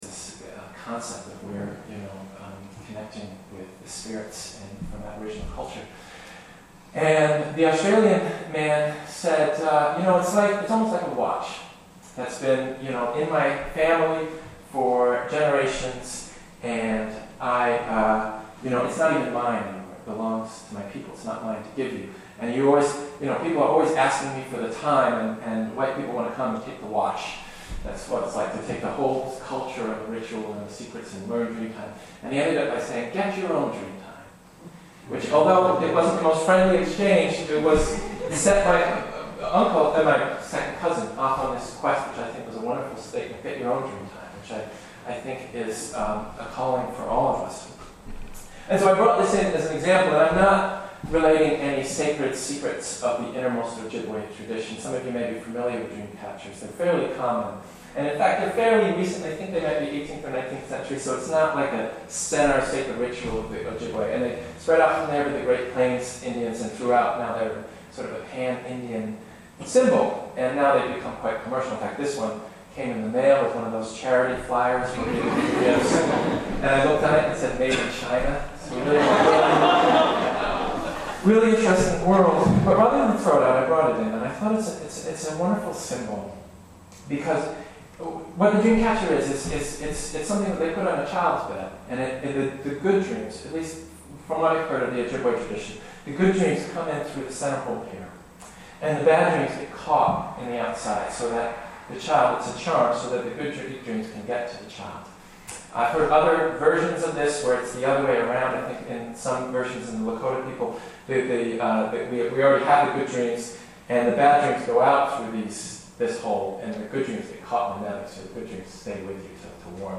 Dreamcatchers sermon (audio)